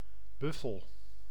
Ääntäminen
Synonyymit bizon kafferbuffel karbouw waterbuffel Ääntäminen : IPA: [ˈby.fəl] Haettu sana löytyi näillä lähdekielillä: hollanti Käännös Ääninäyte Substantiivit 1. buffalo UK US Suku: m .